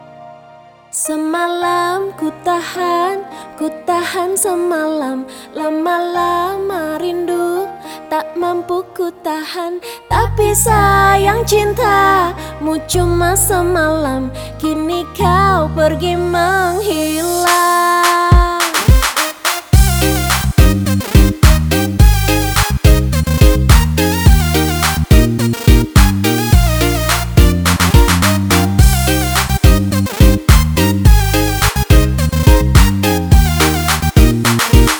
# Dangdut